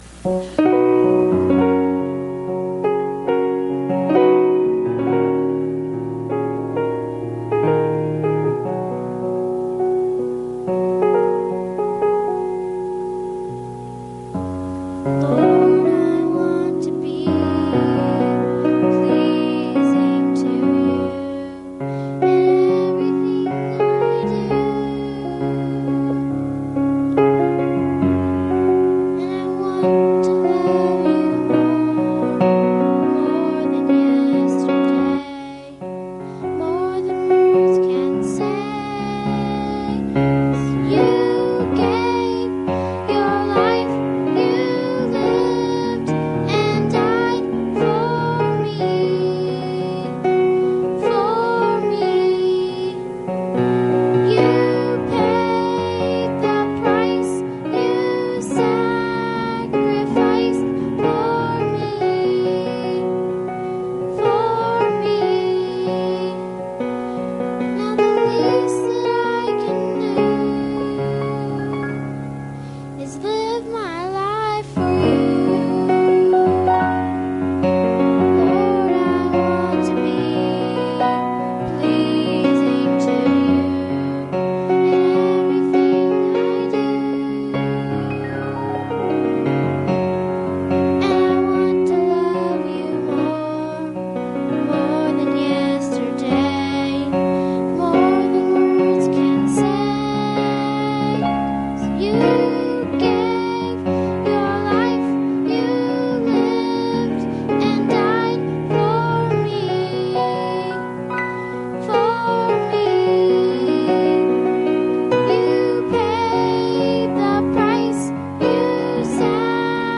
Passage: John 7:24 Service Type: Sunday Morning